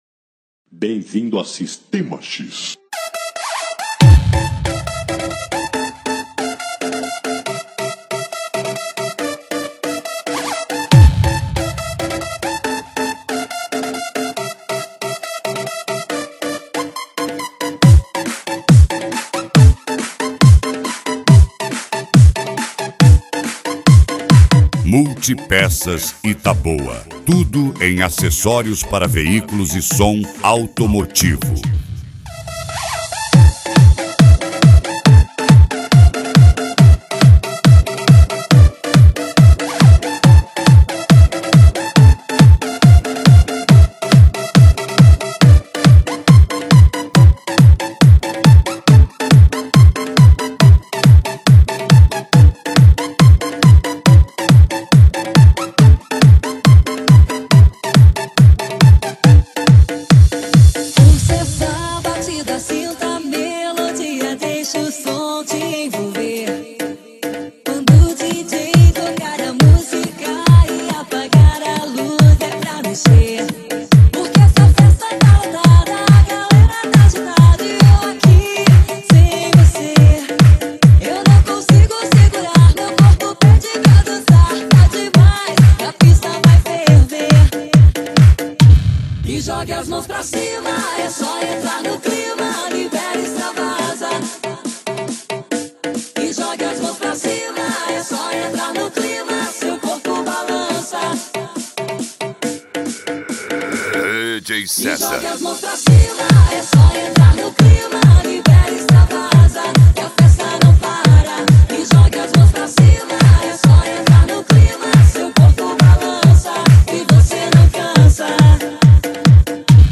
PANCADÃO